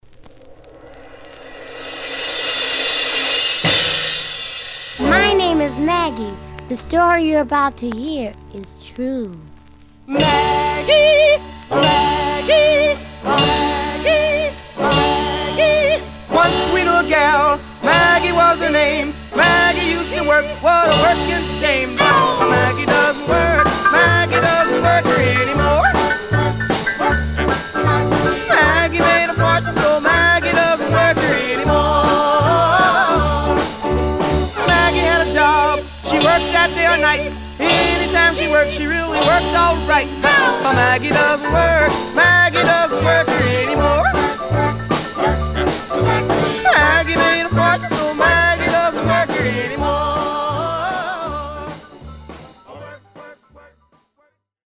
Male Black Groups